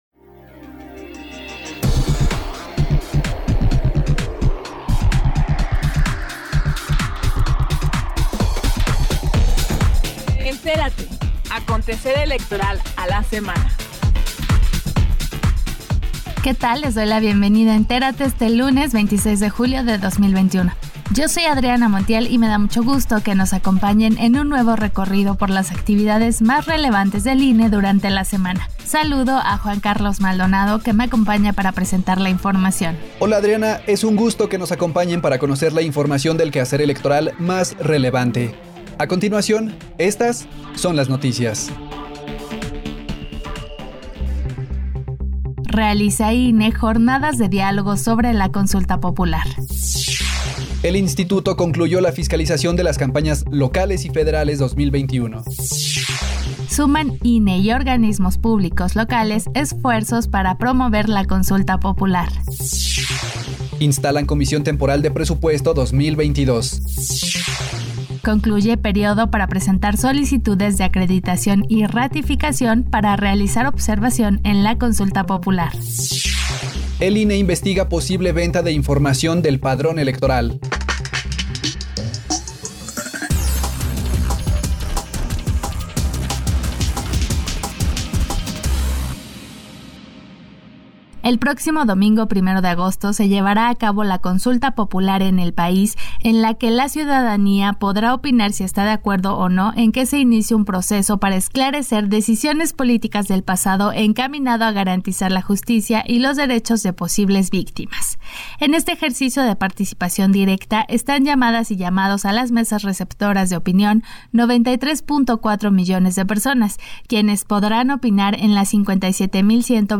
NOTICIARIO 26 JULIO 21 - Central Electoral